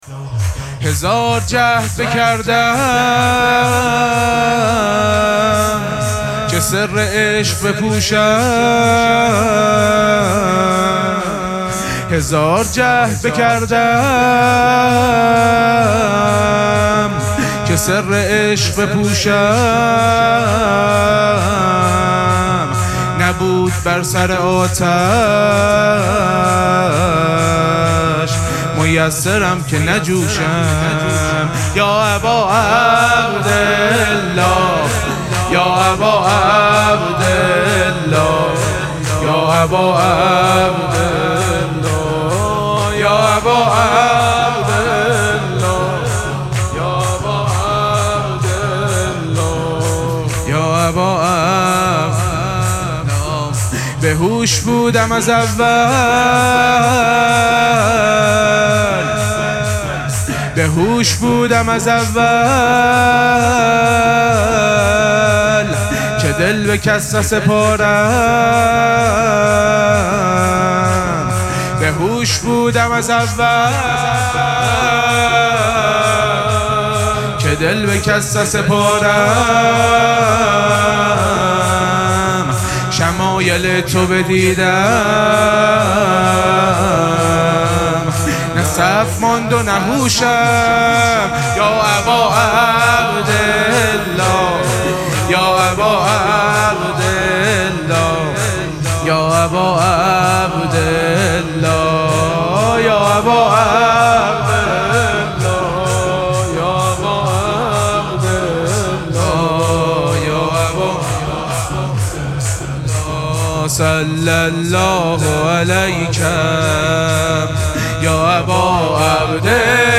مراسم مناجات شب هفدهم ماه مبارک رمضان
شور
مداح